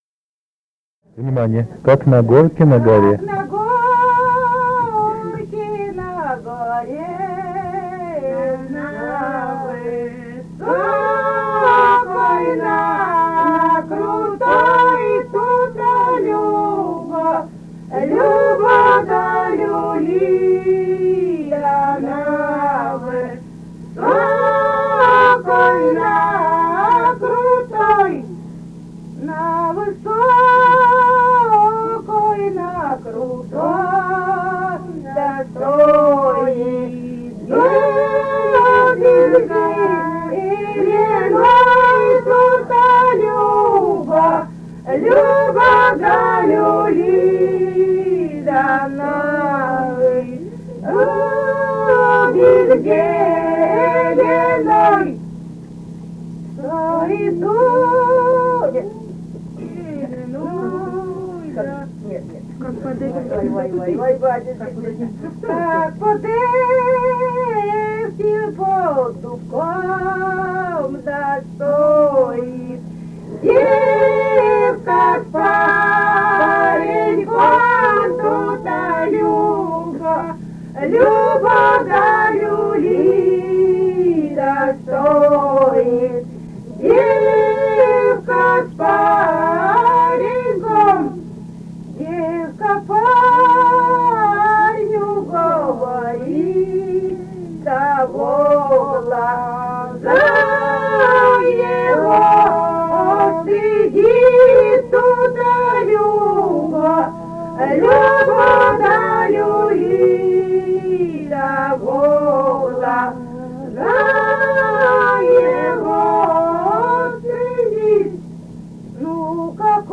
полевые материалы
«Как на горке, на горе» (хороводная).
Костромская область, с. Угольское Островского района, 1964 г. И0792-02